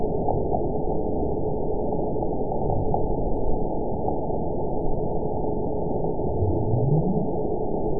event 922262 date 12/29/24 time 00:38:10 GMT (5 months, 3 weeks ago) score 9.52 location TSS-AB03 detected by nrw target species NRW annotations +NRW Spectrogram: Frequency (kHz) vs. Time (s) audio not available .wav